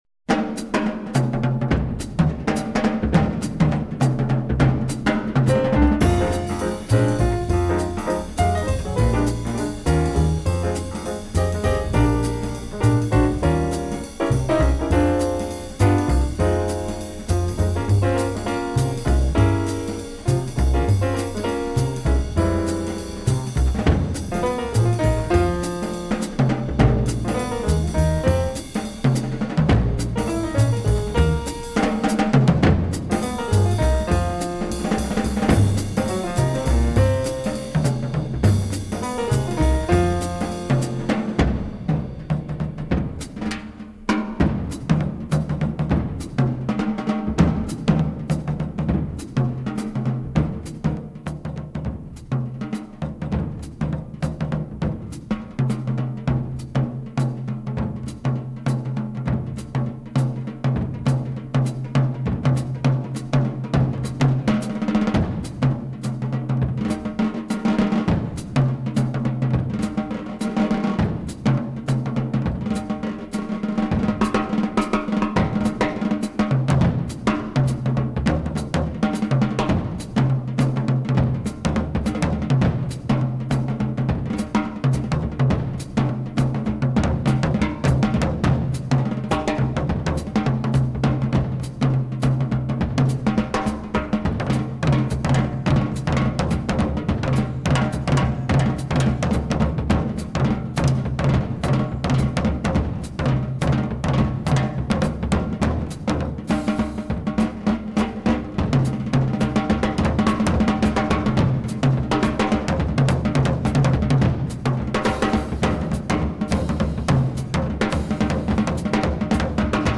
in 5/4 time
piano
bass.